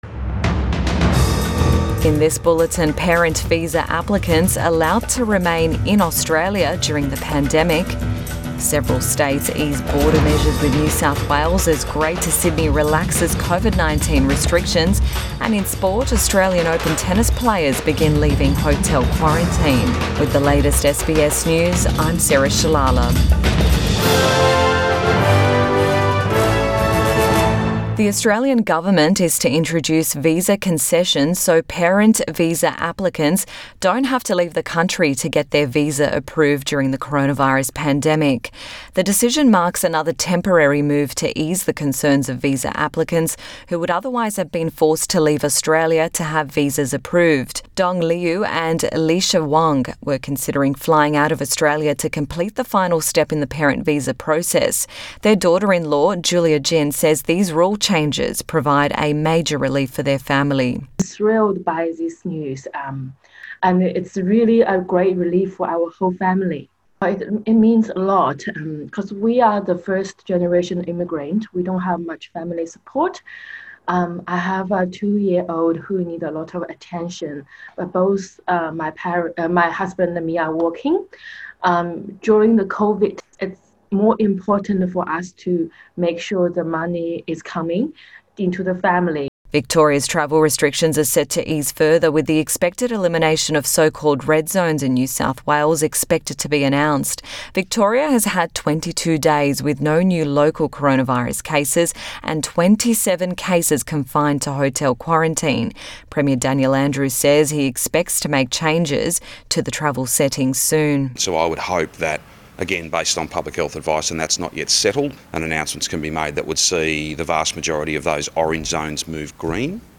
AM Bulletin 29 January 2021